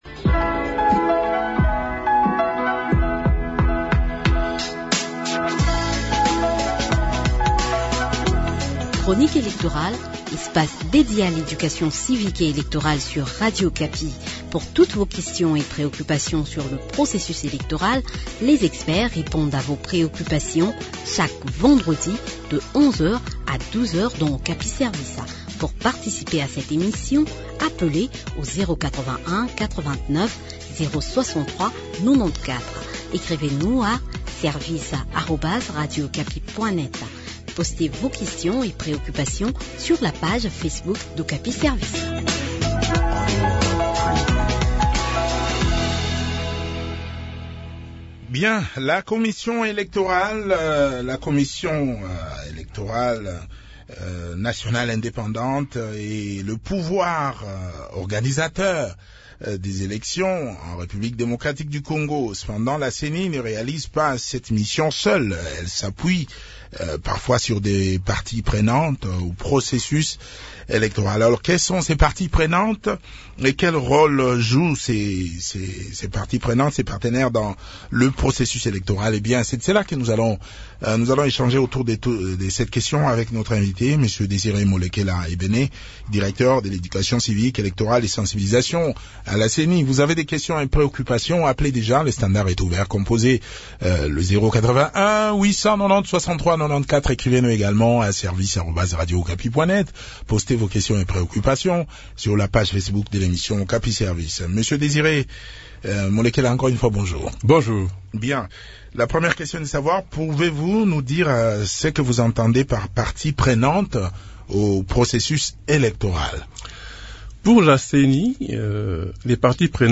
Les éléments de réponse dans cet entretien